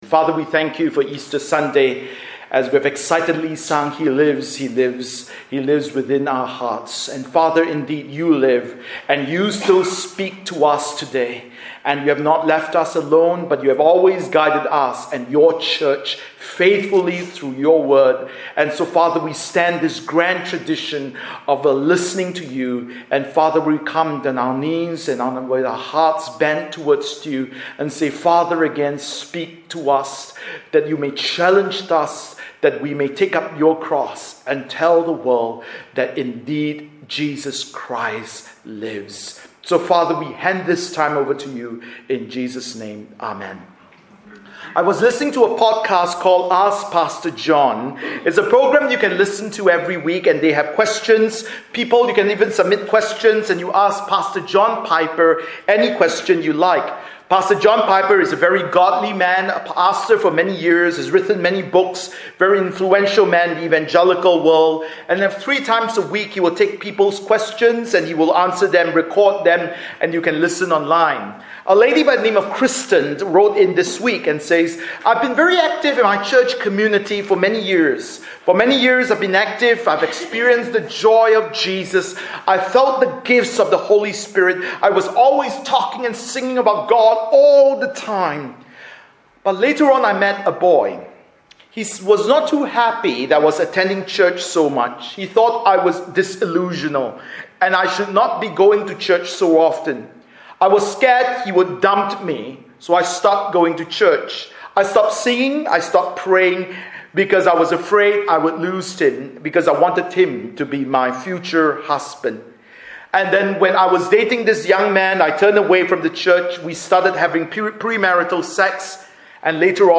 Bible Text: Mark 15:42-16:8 | Preacher